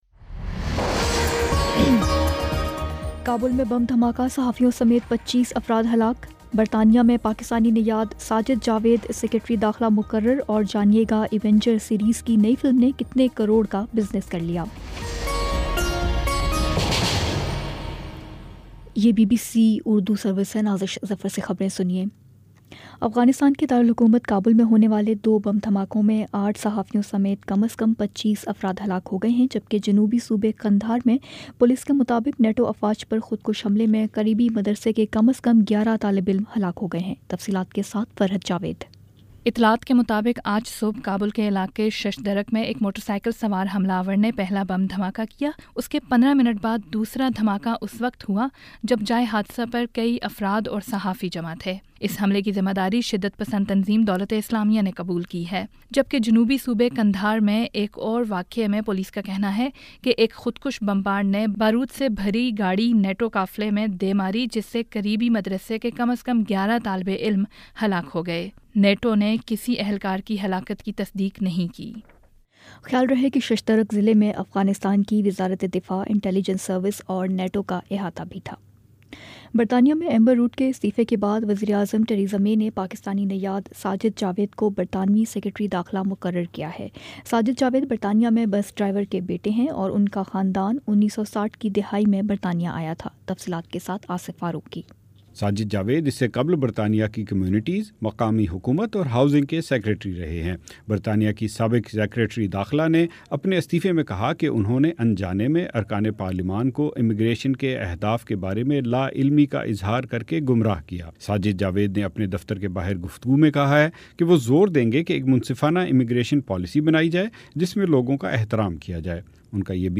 اپریل 30 : شام چھ بجے کا نیوز بُلیٹن
دس منٹ کا نیوز بُلیٹن روزانہ پاکستانی وقت کے مطابق شام 5 بجے، 6 بجے اور پھر 7 بجے۔